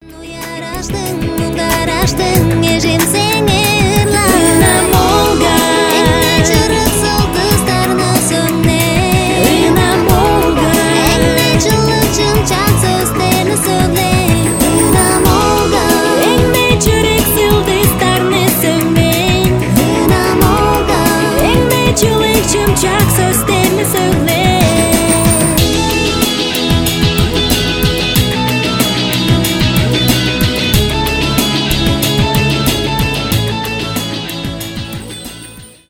поп
красивый женский голос